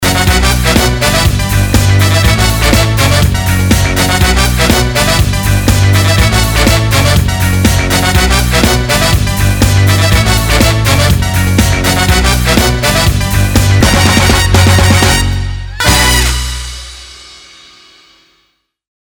Проигрыш из песни